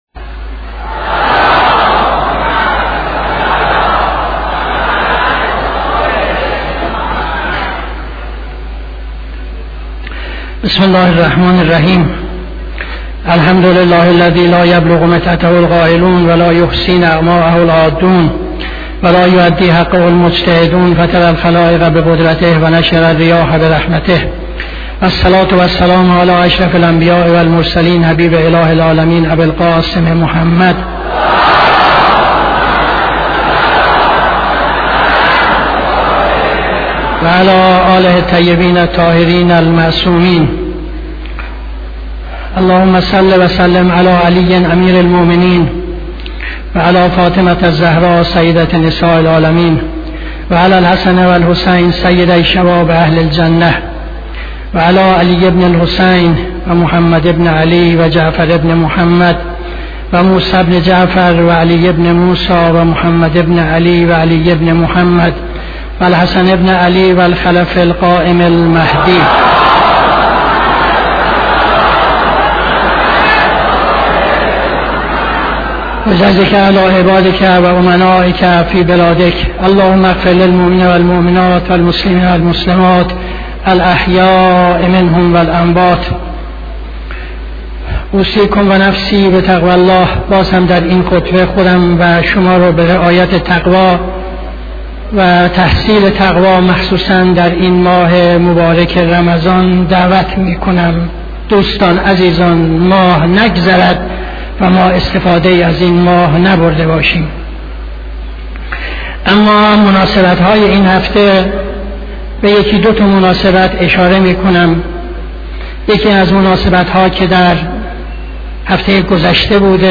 خطبه دوم نماز جمعه 21-10-75